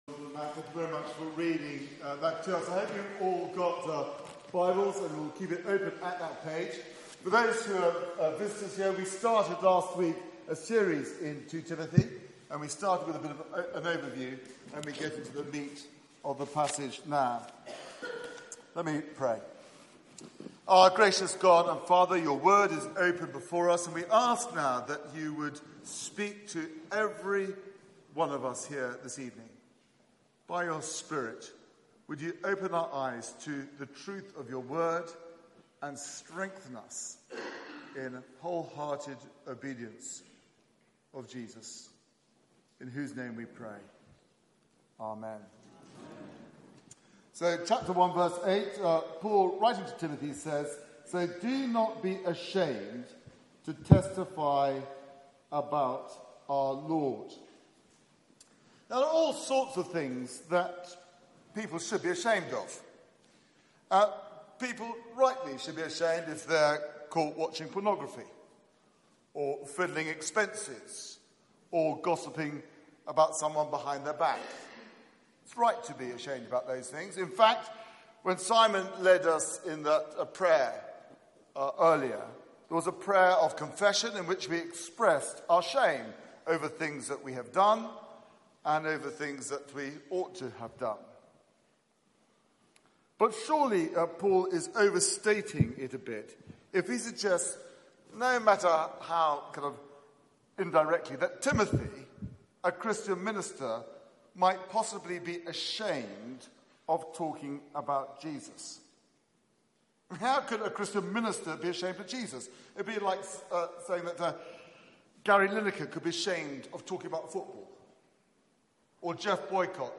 Media for 6:30pm Service on Sun 09th Sep 2018 18:30
Sermon (Poor quality recording)Play